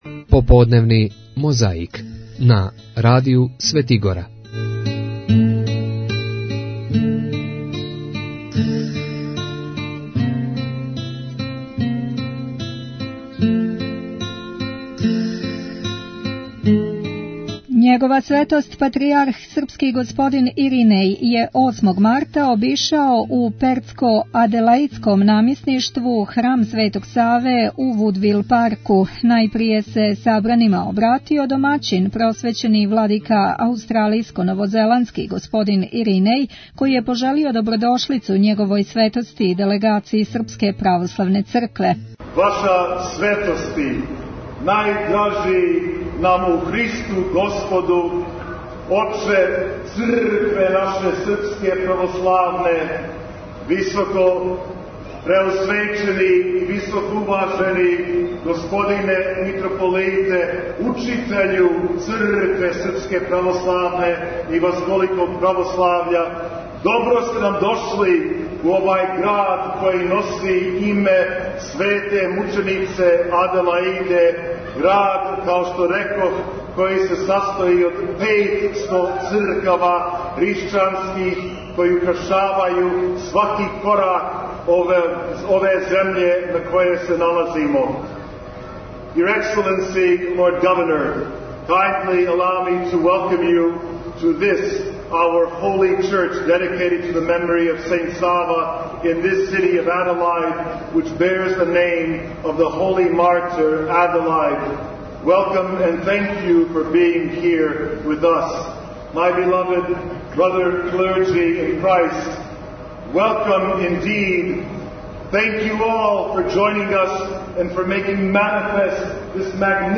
Његова Светост Патријарх српски Иринеј је 8. марта обишао у Пертско-аделаидском намесништву посетио и Храм Св. Саве у у Вудвил Парку. Најпре се обратио домаћин, Епископ Иринеј, који је пожелео добродошлицу Његовој Светости и делегацији СПЦ, а затим је Патријарх беседио Србима о чувању вере православне, традиције и језика.